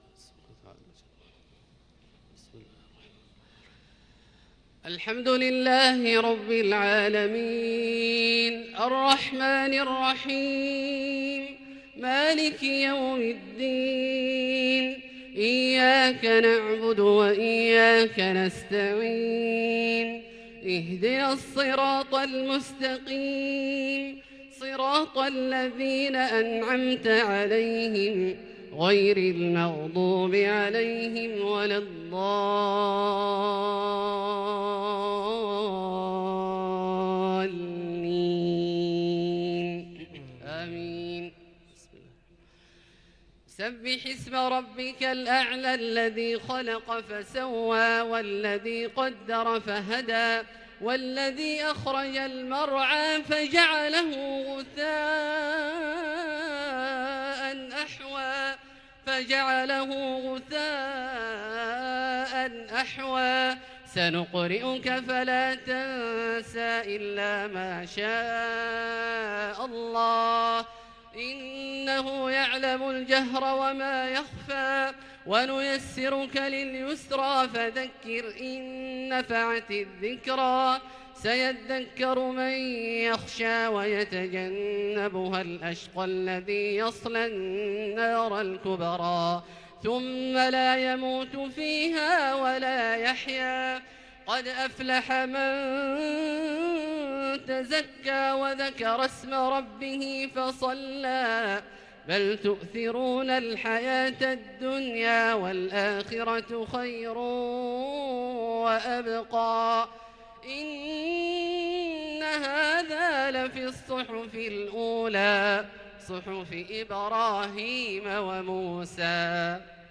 Jumuah prayer 7th of Aug 2020 Surah Al-A'laa and Al-Ghaashiya > 1441 H > Prayers - Abdullah Al-Juhani Recitations